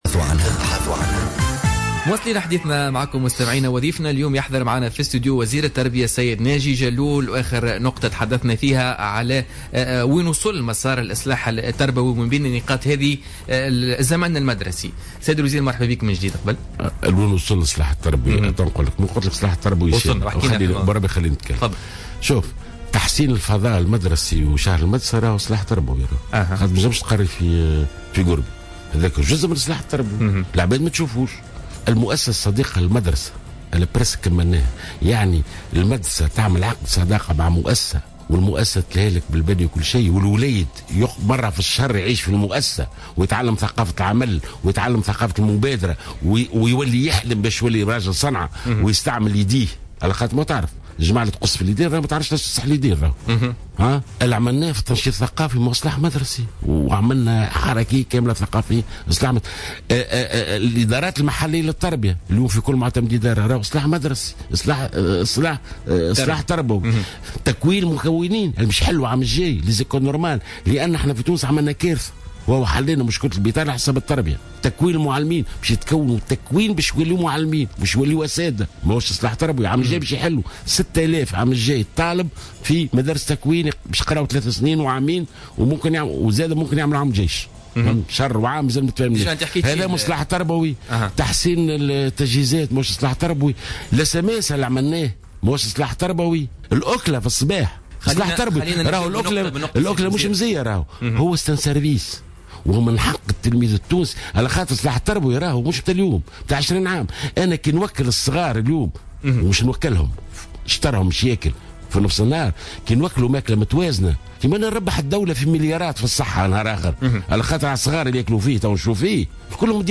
أكد وزير التربية ناجي جلول ضيف بوليتيكا اليوم الجمعة 22 أفريل 2016 أن المشكل ليس في موعد انطلاق السنة المدرسية بل في المقاييس التي سيتم على أساسها تنظيم الزمن المدرسي.